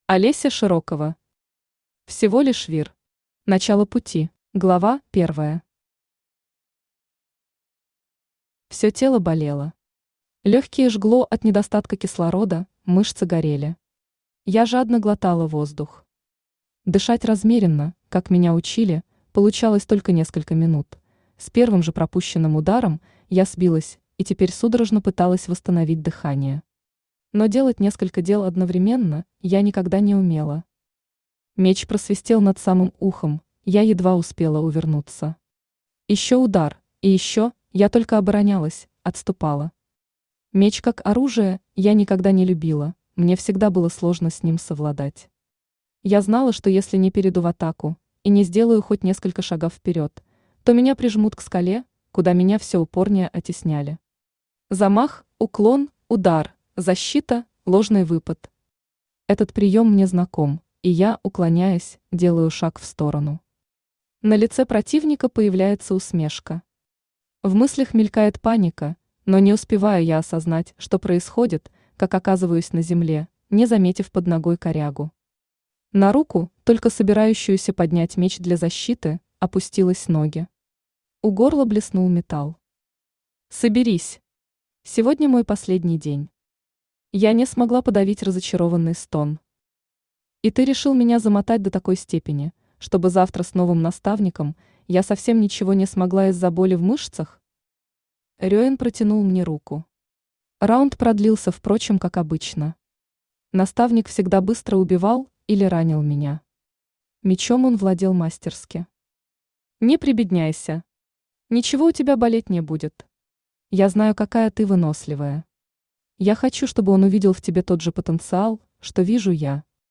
Начало пути Автор Олеся Вячеславовна Широкова Читает аудиокнигу Авточтец ЛитРес.